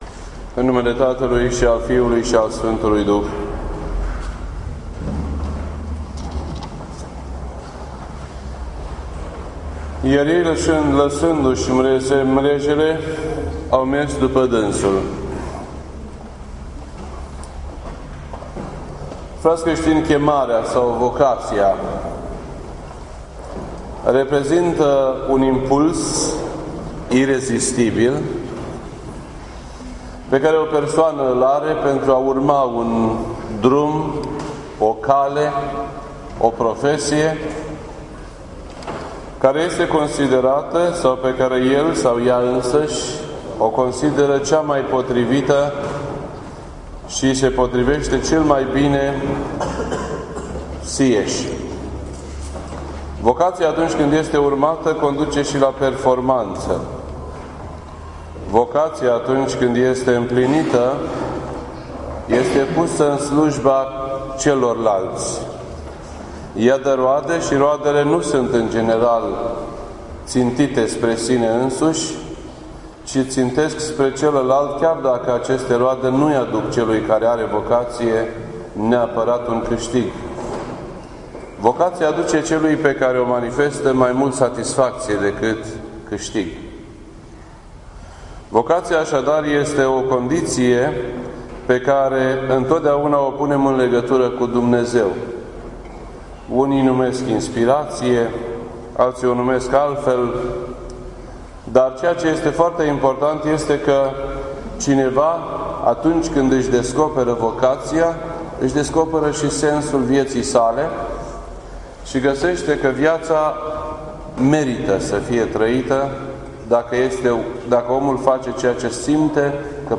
This entry was posted on Sunday, June 14th, 2015 at 3:58 PM and is filed under Predici ortodoxe in format audio.